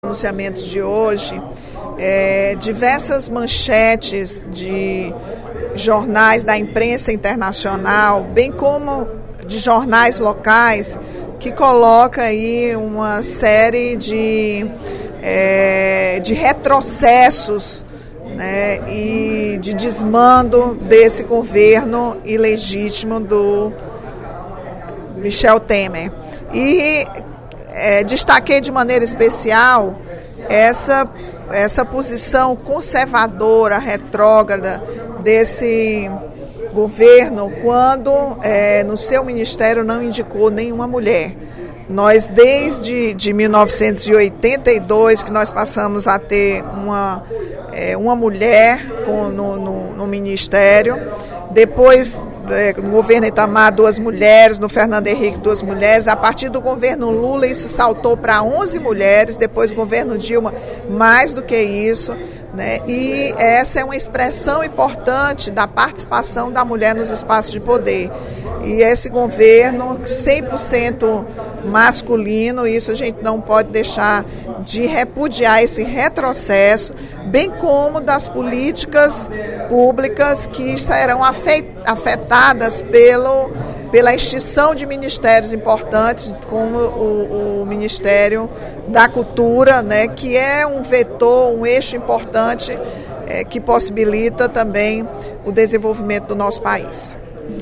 A deputada Rachel Marques (PT) destacou, no primeiro expediente da sessão plenária desta terça-feira (17/05), a repercussão negativa dada por diversos veículos de comunicação nacionais e internacionais às primeiras ações adotadas pelo presidente interino da República, Michel Temer.
Em aparte, os deputados Elmano Freitas (PT) e Dr. Santana (PT) endossaram as críticas de Rachel Marques, salientando que também não reconhecem o novo governo e demonstrando preocupação com a possibilidade de retrocesso em políticas públicas valiosas para a sociedade brasileira.